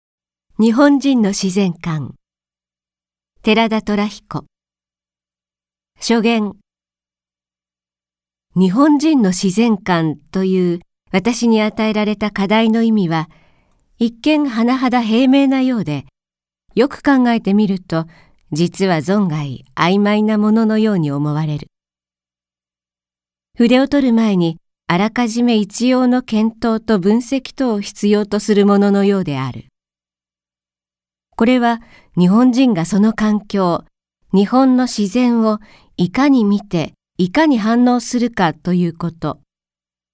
朗読ＣＤ　朗読街道142「日本人の自然観」寺田寅彦
朗読街道は作品の価値を損なうことなくノーカットで朗読しています。